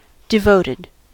devoted: Wikimedia Commons US English Pronunciations
En-us-devoted.WAV